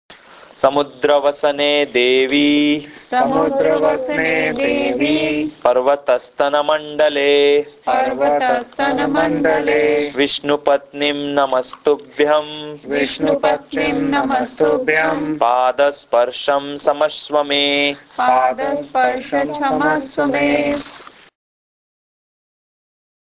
It’s no wonder then that Hindus begin each day with a prayer to Mother Earth – a Morning Shloka that is to be recited before you put your feet on the ground (